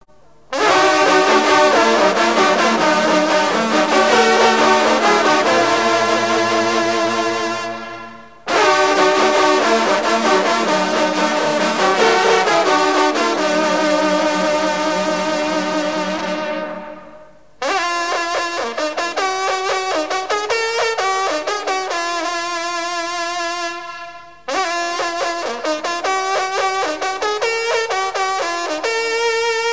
circonstance : rencontre de sonneurs de trompe
Pièce musicale éditée